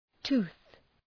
Προφορά
{tu:ɵ}